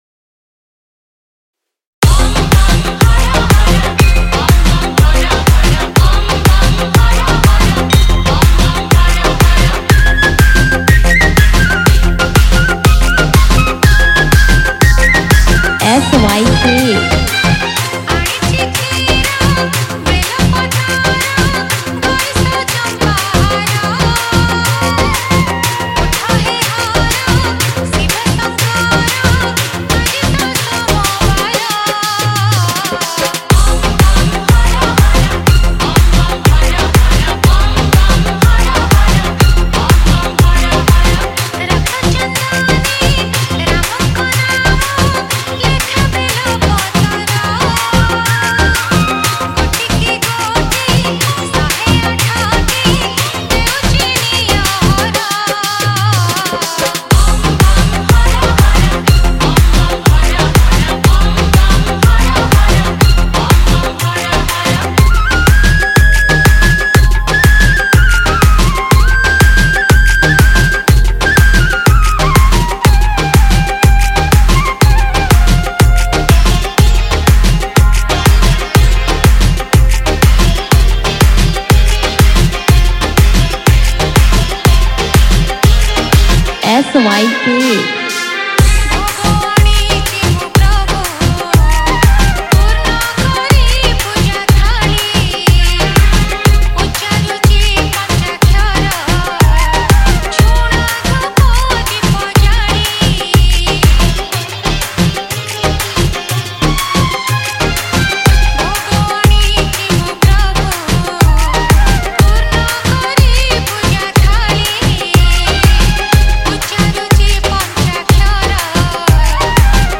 Shivratri Special DJ Remix Songs Songs Download